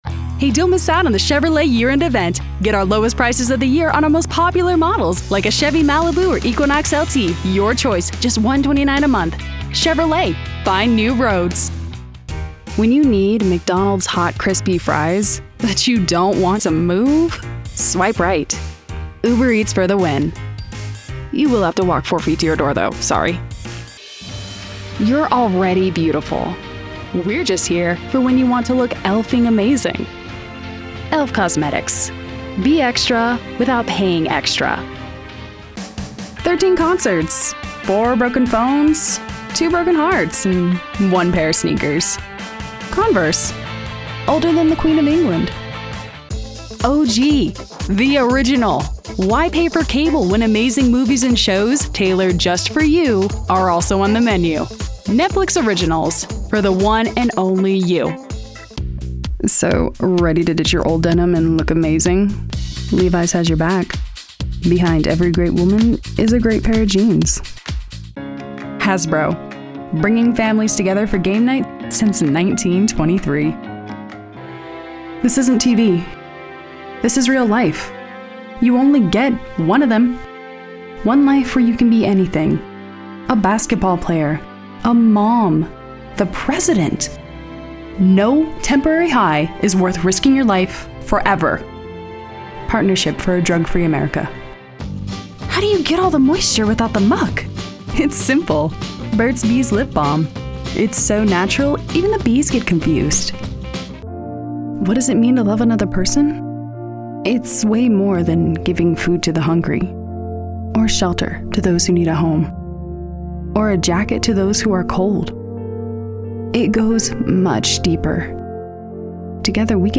Voiceover Artist,
Sex: Female
Ages Performed: Teen, Young Adult, Middle Age,
Rode NT2-A, Focusrite Scarlett Solo 2nd gen 2-in/2-out, Source Connect standard, Computer
Demos